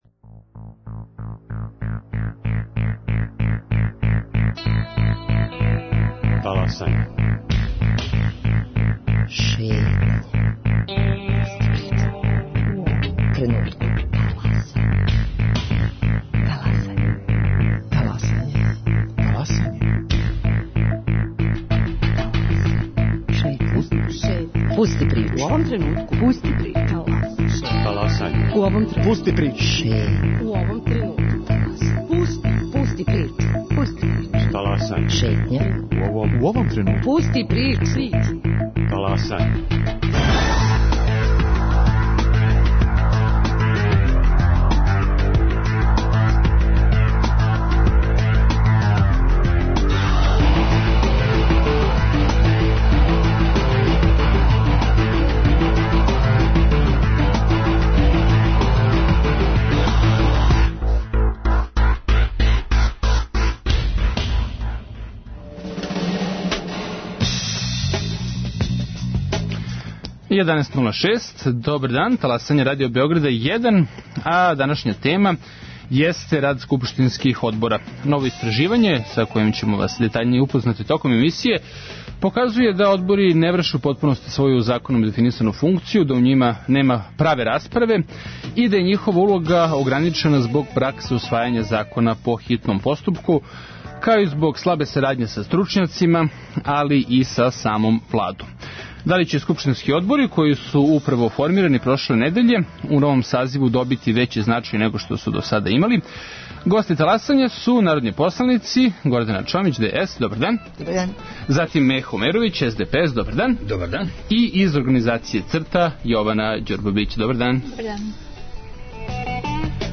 Гости Таласања су посланици Гордана Чомић (ДС) и Мехо Омеровић (СДПС)